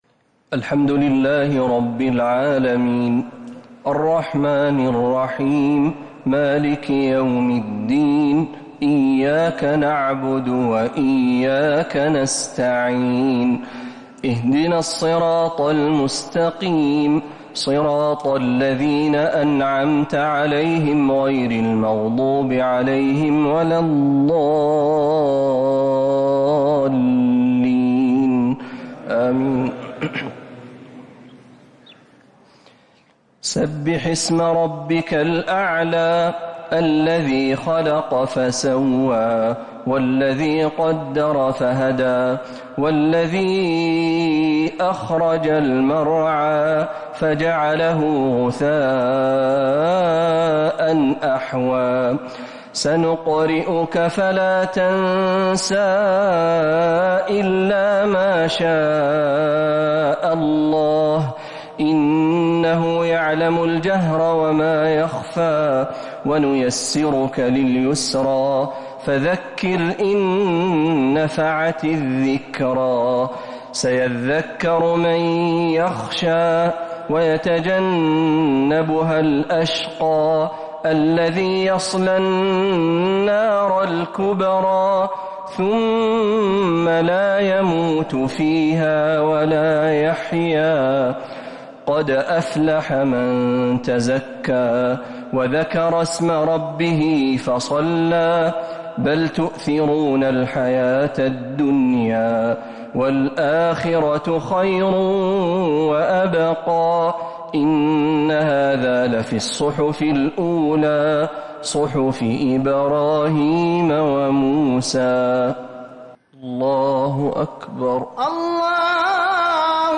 صلاة الشفع و الوتر ليلة 1 رمضان 1447هـ | Witr 1st night Ramadan 1447H > تراويح الحرم النبوي عام 1447 🕌 > التراويح - تلاوات الحرمين